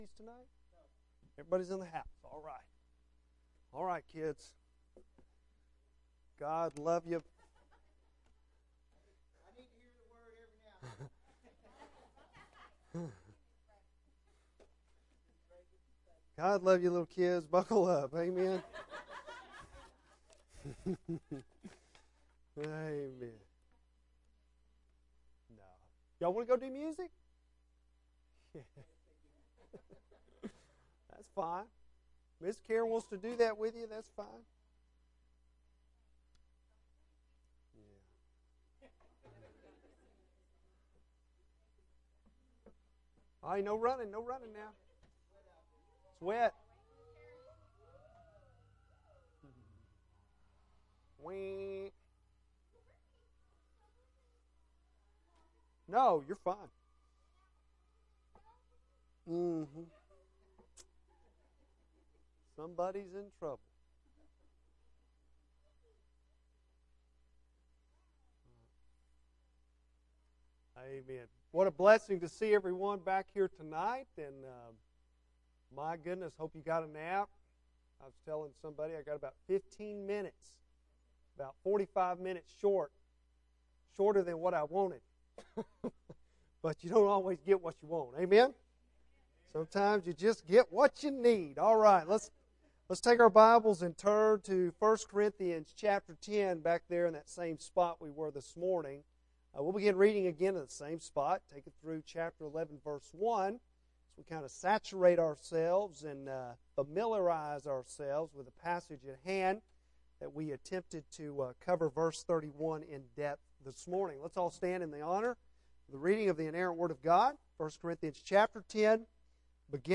Bible Text: I Corinthians 10:23-32; 11:1 | Preacher: